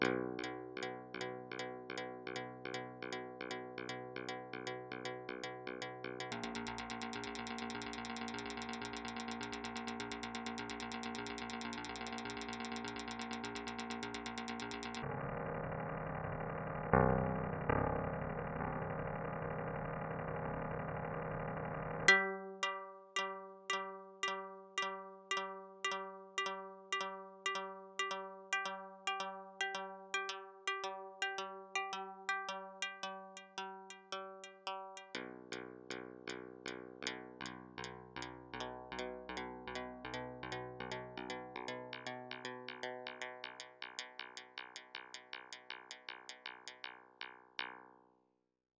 Two strings excited with a force controller. With adjustment of the length of each string and possibility to move the positions of input accesses (manually or with a line)
Stereo output